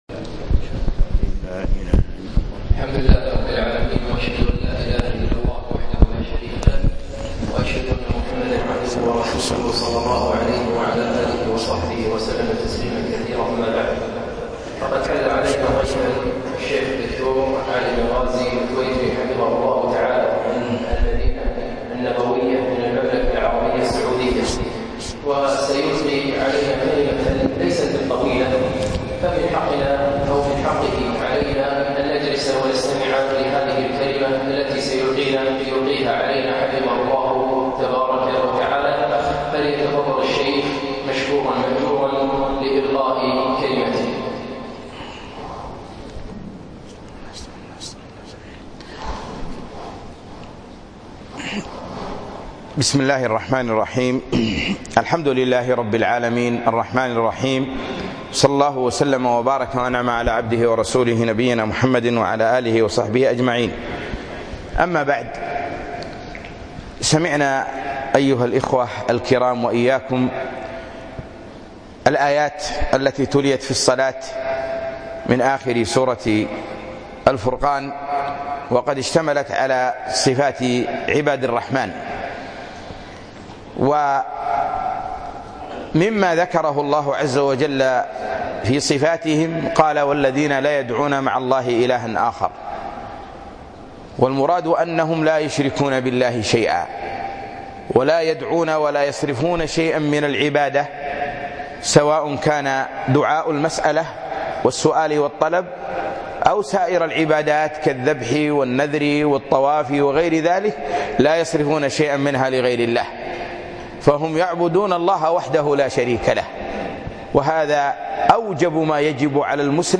كلمة في مسجد ضاحية الفردوس